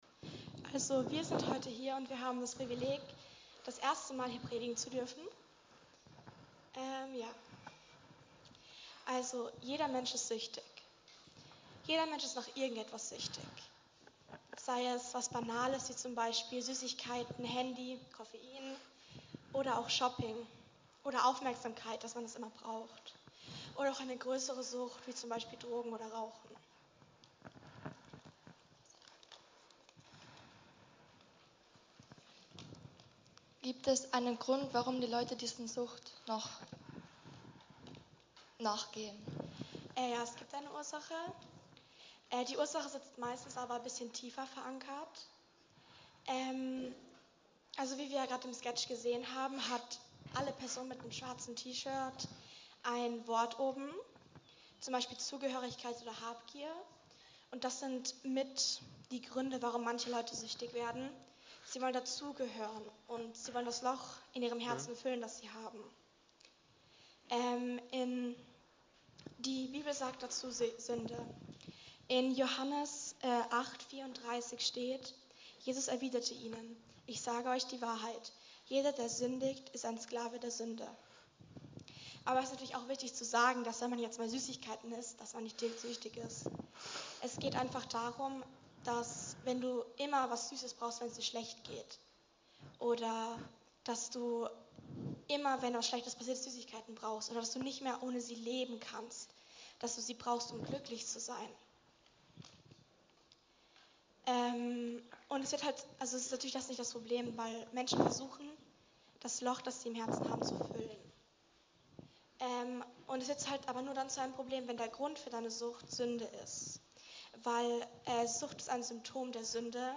Eine predigt aus der serie "Einzelpredigten 2026."